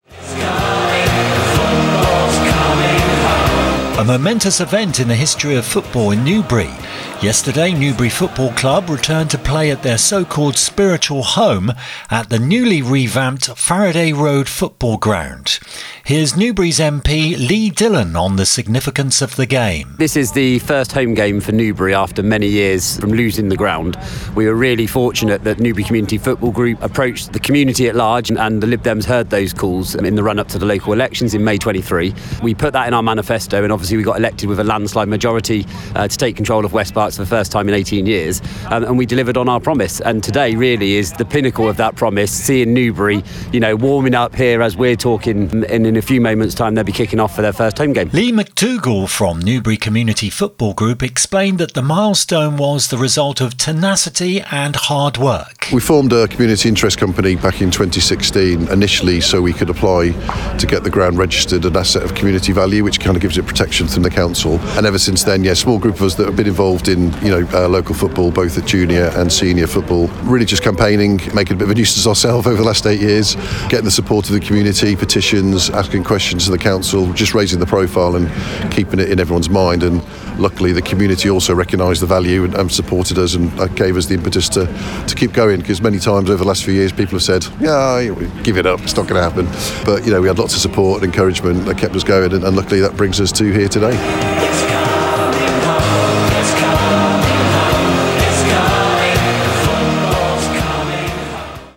Click below to hear how Kennet Radio reported the momentous occasion on our local news: